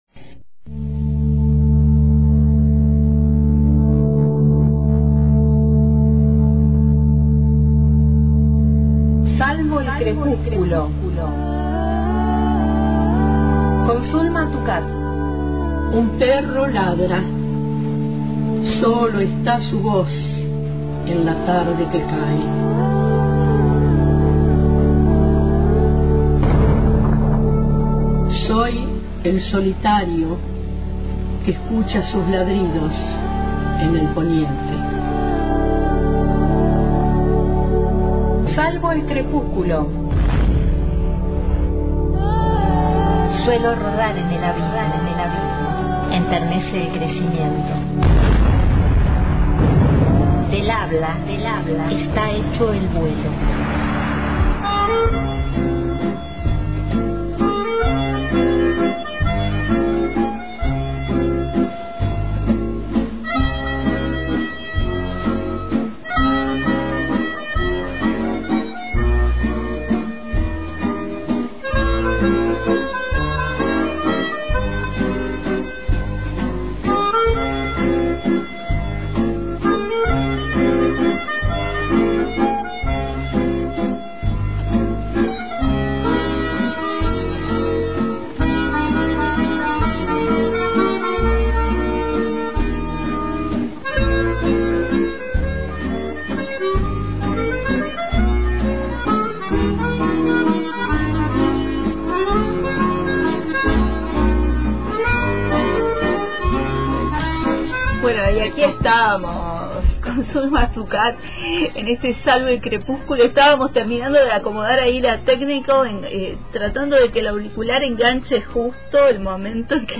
Las canciones las transformo en poesía y escuchamos un breve fragmento de una entrevista sobre cómo lo realizó.